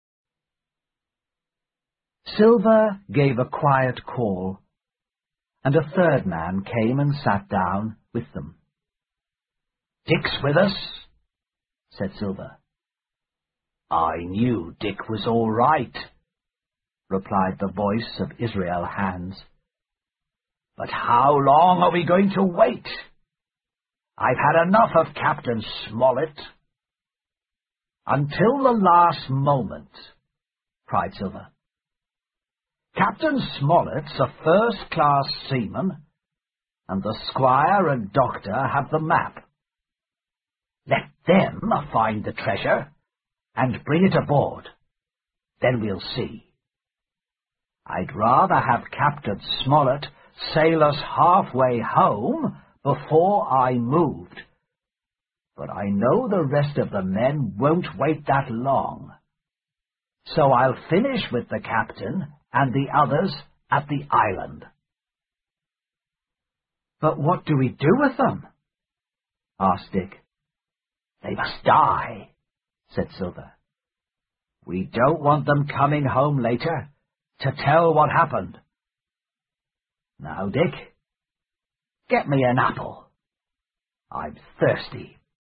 在线英语听力室《金银岛》第七章 苹果桶(5)的听力文件下载,《金银岛》中英双语有声读物附MP3下载